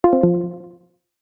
Звуки Discord
Разъединено